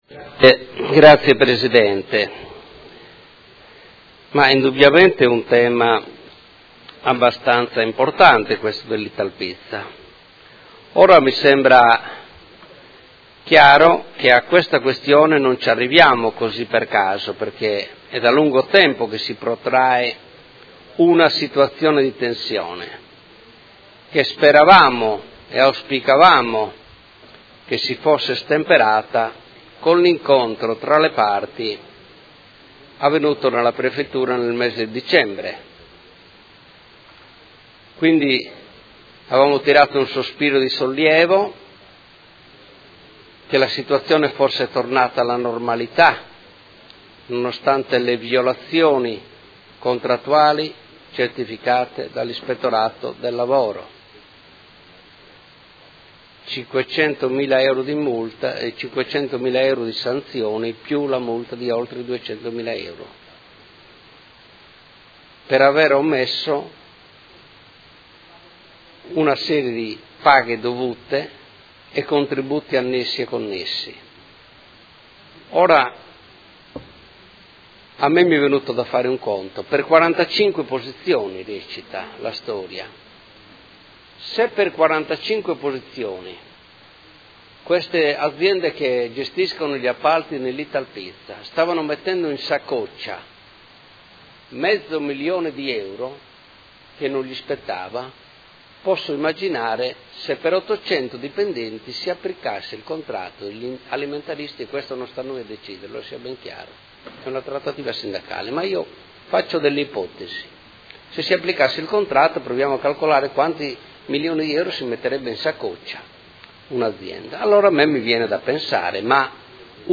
Seduta del 24/01/2019. Dibattito su interrogazione, mozioni ed emendamento riguardanti la situazione Società Italpizza S.p.A